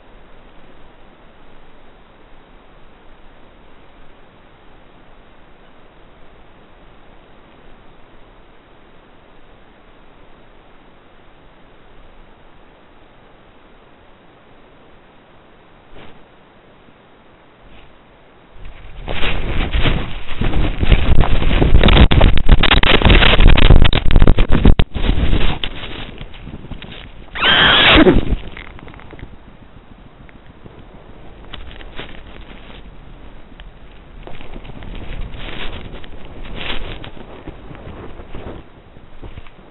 Appendix A5 (Supplementary Materials 5): Chase and hare kill (harecall.wav) | Digital Collections